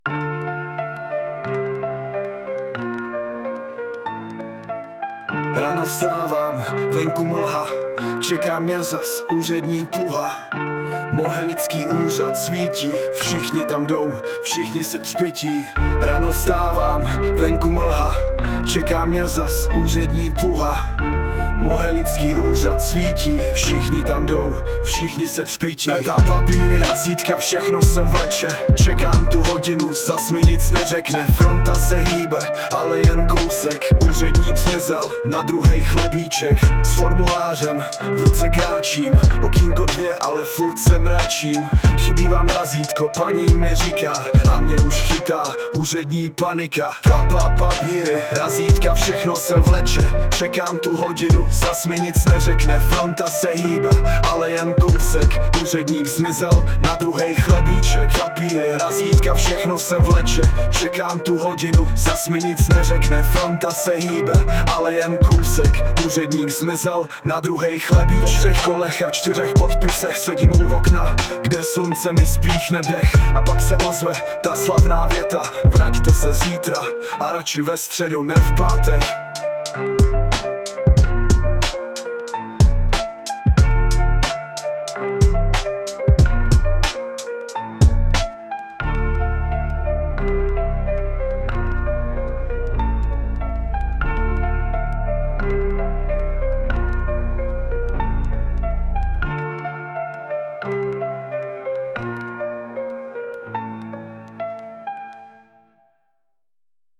Na portálu zadáte své požadavky, např. romantická písnička o šikovných úřednicích na Městském úřadě Mohelnice, a AI písničku vygeneruje včetně nazpívání:
A jak to zní i se zpěvem, to si poslechněte zde: